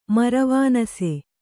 ♪ maravānase